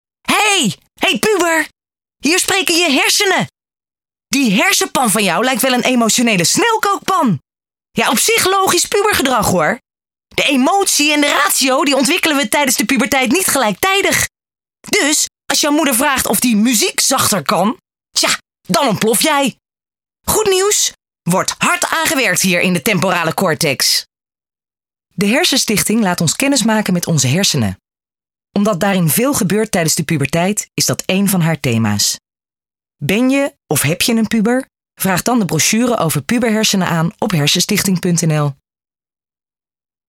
Commercials:
Hersenstichting (overtuigend):